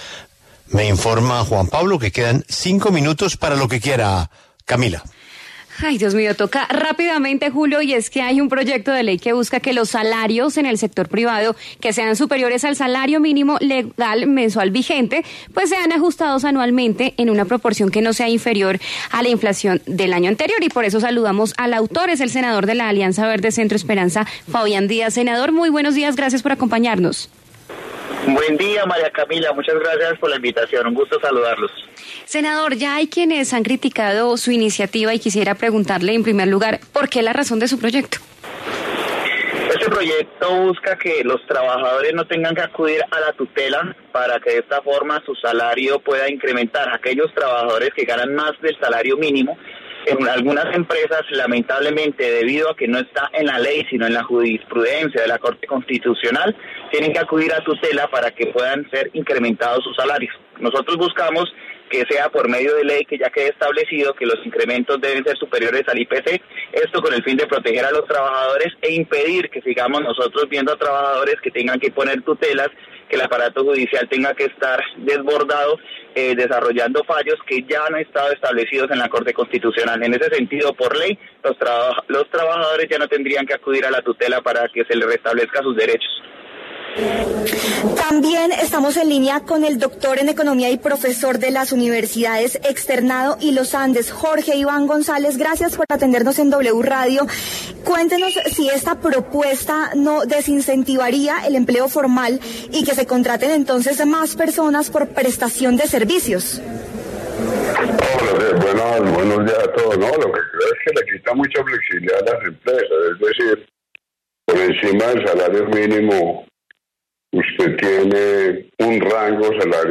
En La W habló el autor de la iniciativa, el senador Fabián Díaz.